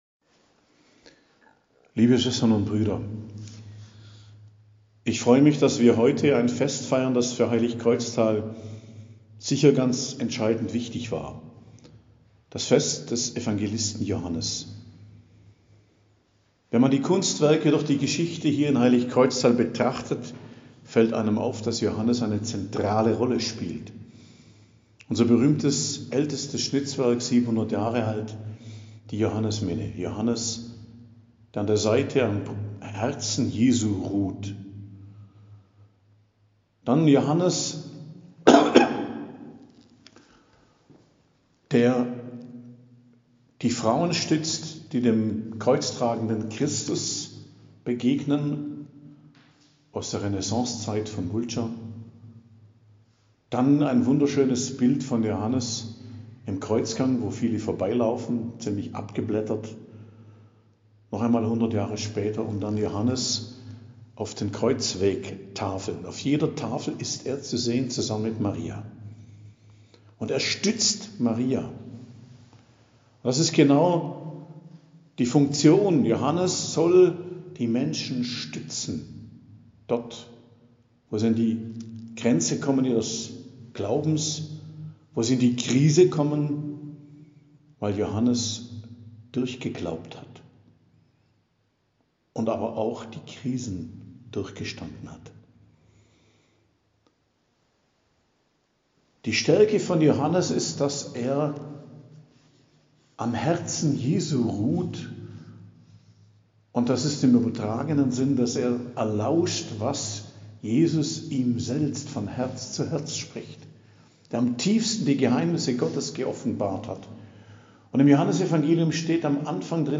Predigt am Fest des Hl Johannes, Apostel und Evangelist, 27.12.2022 ~ Geistliches Zentrum Kloster Heiligkreuztal Podcast